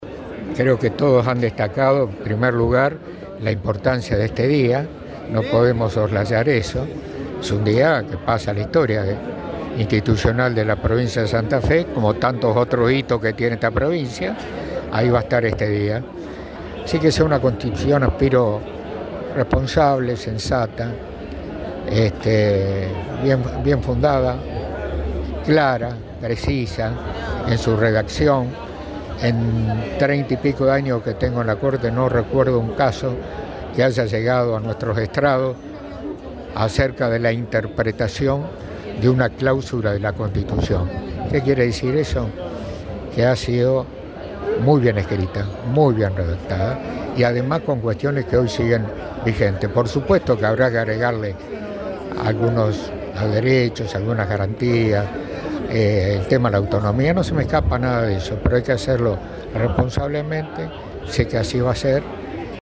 Durante el acto de apertura, realizado este lunes en la Legislatura de Santa Fe, Falistocco subrayó la relevancia del momento y llamó a los convencionales a trabajar con responsabilidad y precisión para modernizar la Carta Magna.
ROBERTO-FALISTOCCO-PTE-CORTE-SUPREMA-TOMO-JURAMENTO-A-REFORMADORES.mp3